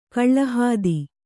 ♪ kaḷḷa hādi